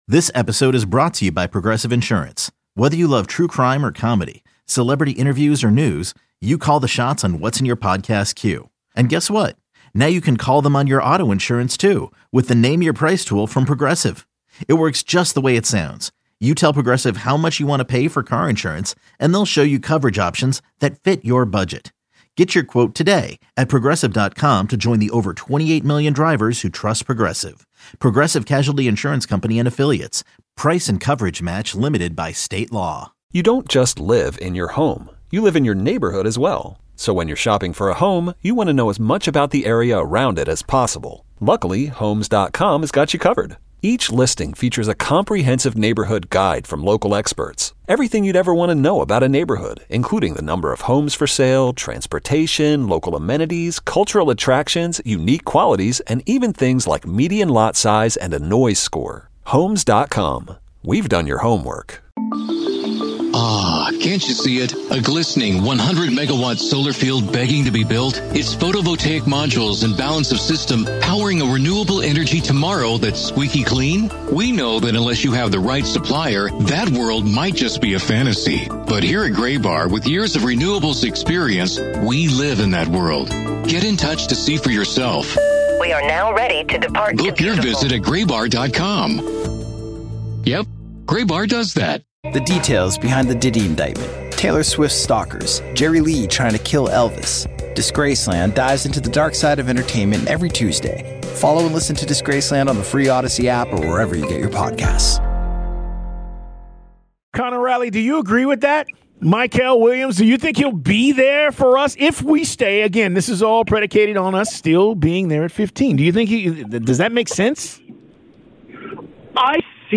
Listen to the best 92-9 the Game guest interviews of the week